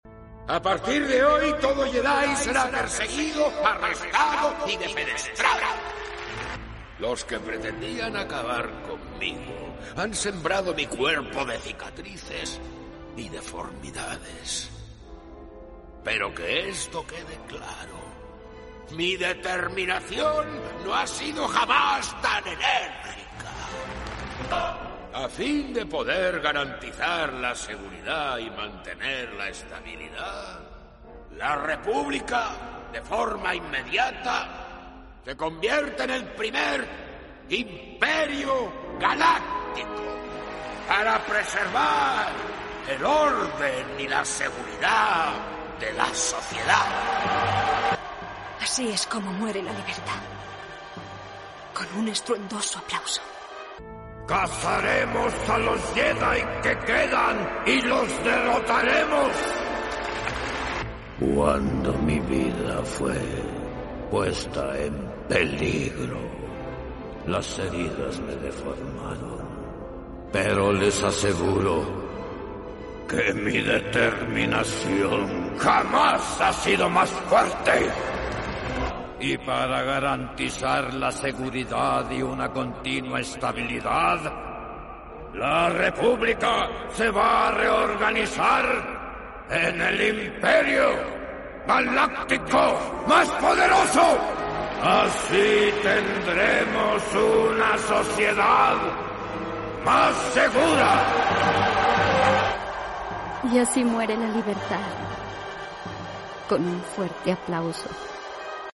Discurso de Palpatine | Comparación de Doblaje